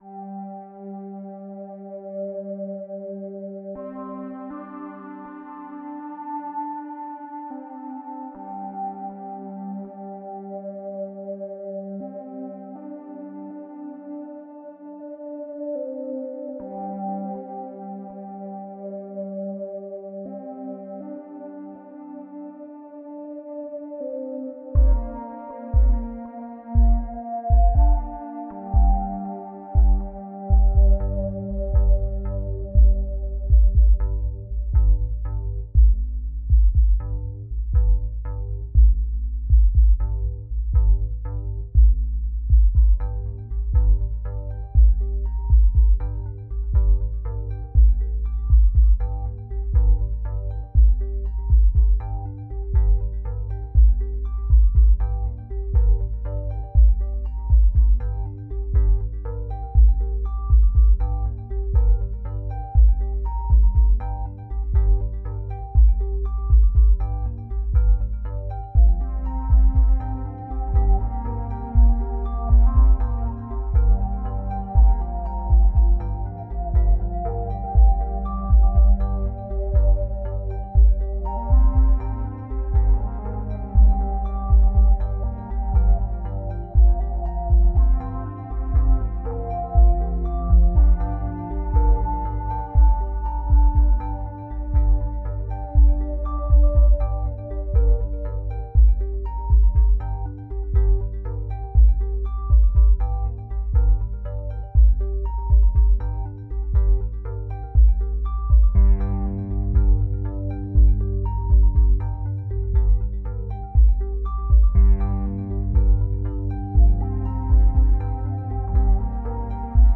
space music has a Cold War feel to it
is made from analogue Yamahas